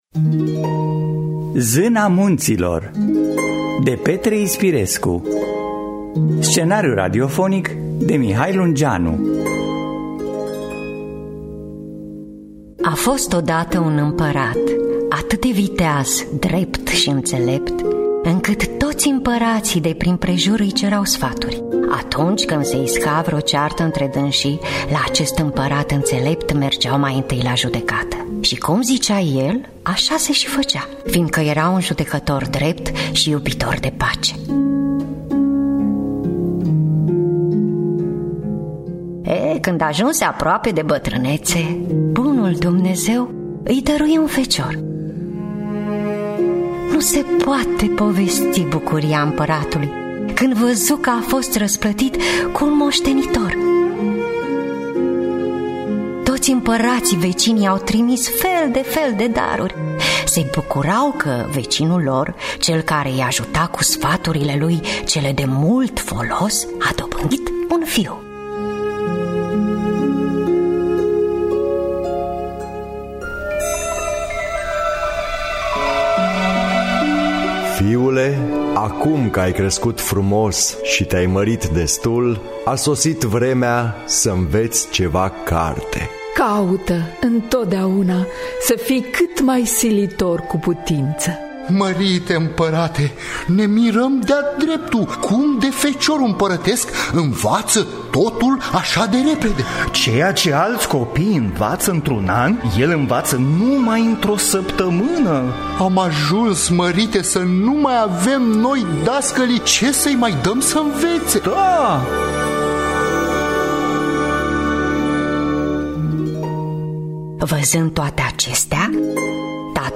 Petre Ispirescu – Zana Muntilor (2017) – Teatru Radiofonic Online